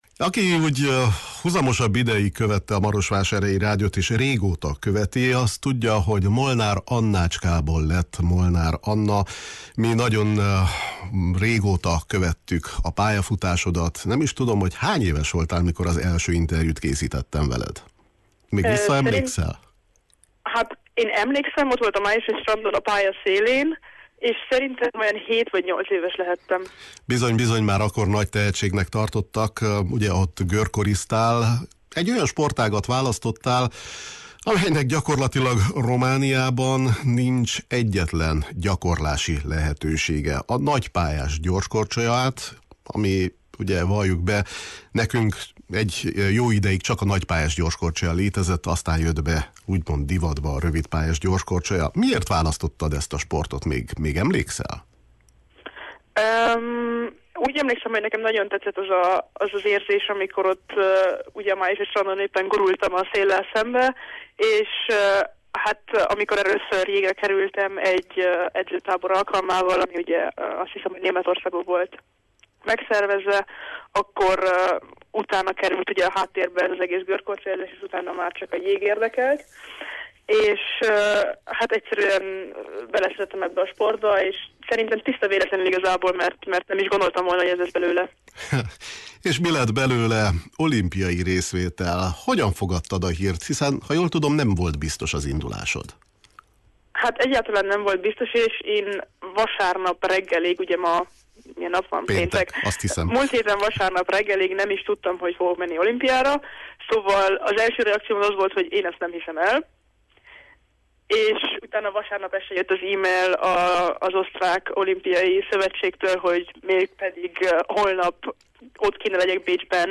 A sportolót csomagolás közben értük utol telefonon